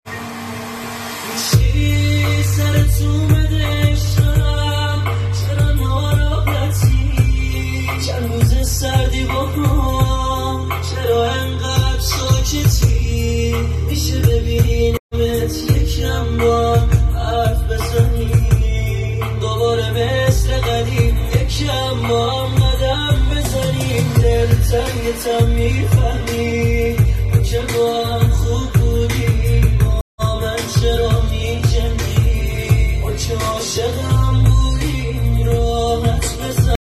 عاشقانه